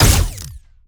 Added more sound effects.
GUNAuto_Plasmid Machinegun B Single_01_SFRMS_SCIWPNS.wav